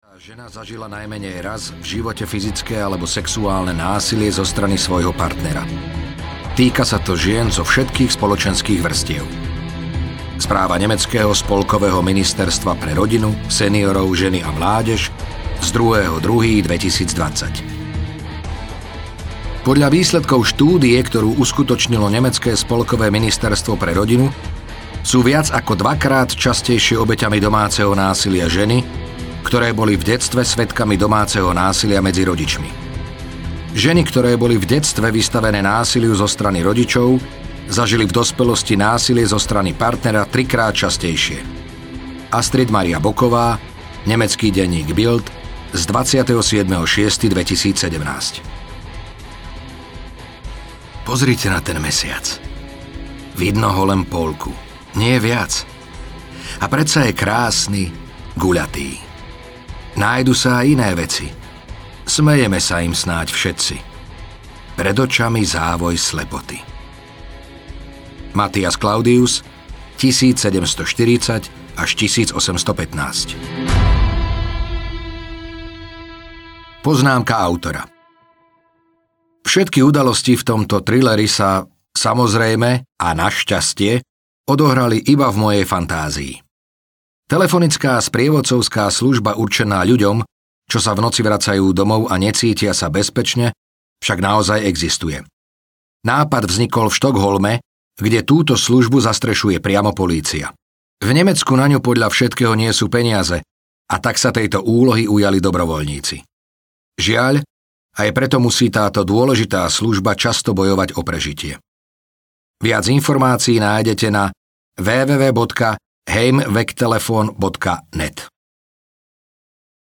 Cesta domov audiokniha
Ukázka z knihy